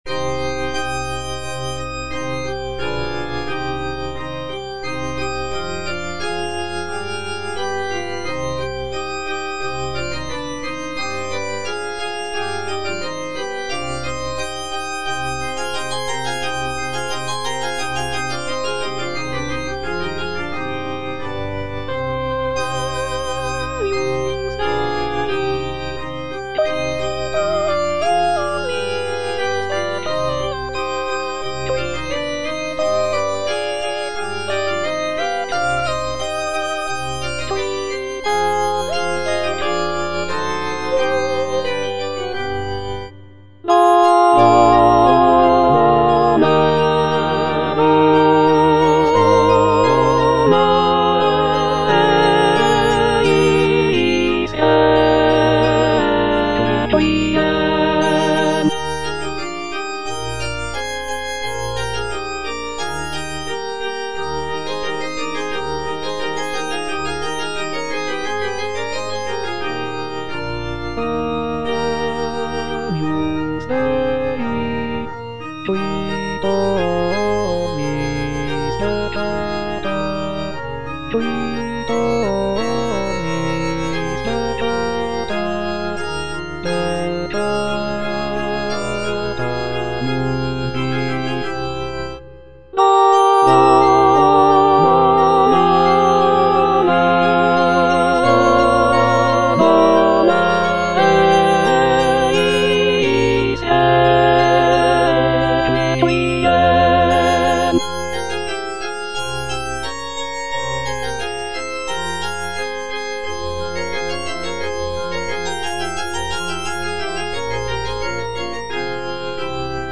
M. HAYDN - REQUIEM IN C (MISSA PRO DEFUNCTO ARCHIEPISCOPO SIGISMUNDO) MH155 Agnus Dei - Alto (Emphasised voice and other voices) Ads stop:
The work is characterized by its somber and mournful tone, reflecting the solemnity of a funeral mass.